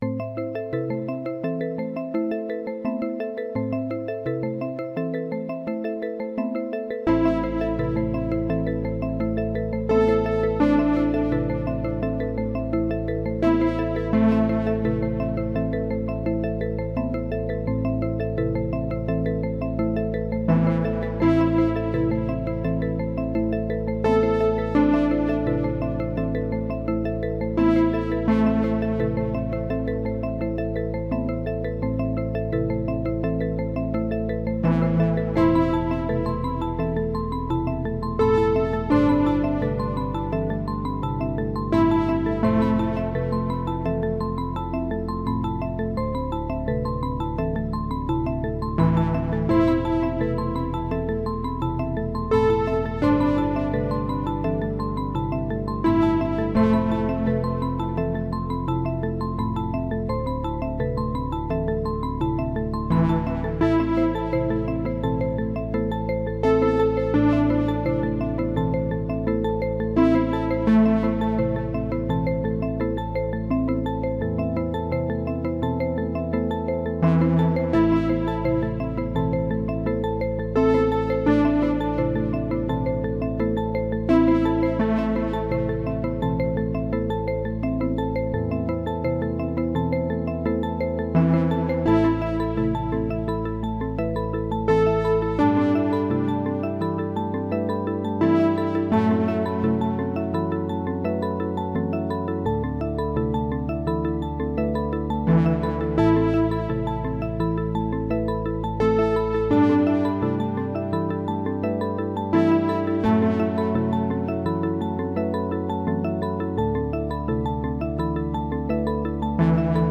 • AE Modular synthesizer rack shown as configured here, notably including three GRAINS modules (two running the MJQ firmware and one running the Booker-M firmware), a Coils module, and a 555 VCO pushed through a Wavefolder.
The goal of the song was to make a minimalist, repetitive, trancelike song where the main melody is repeated over and over, but the bassline and the accompanying arpeggios keep changing.
It sounds sometimes like the marimba arpeggios showup too late.